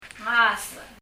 ngasech　　　　[ŋa:sə ! ]
発音